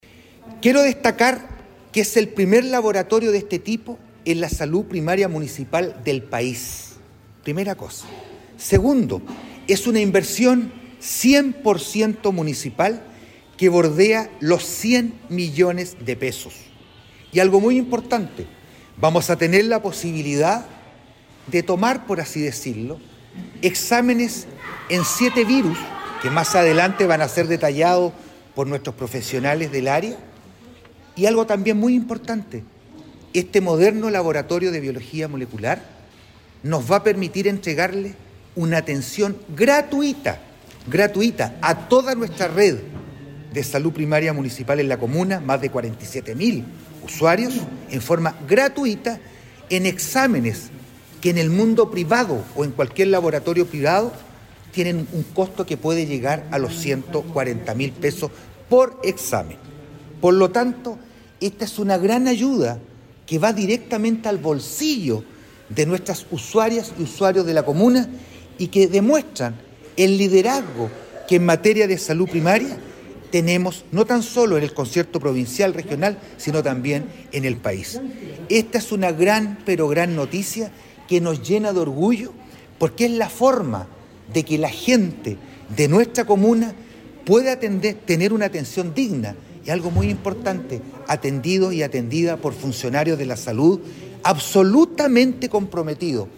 Respecto a lo que significa la puesta en mara de esta infraestructura, el alcalde Vera, señaló: